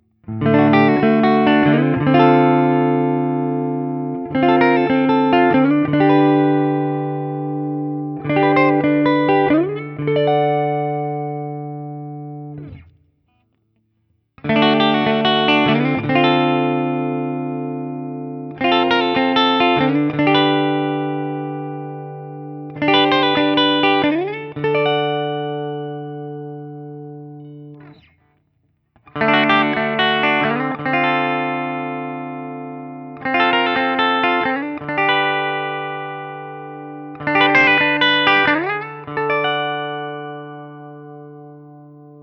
The guitar has a great jangly sound from those LB1 pickups and accentuating that is where I’d find myself the most happy with this instrument.
ODS100 Clean
This is the first time I used my new Axe-FX III for recording which I did direct to Audacity to my Mac Pro.
For each recording I cycle through the neck pickup, both pickups, and finally the bridge pickup. All knobs on the guitar are on 10 at all times.
Guild-TBird-ST-ODS100-D.wav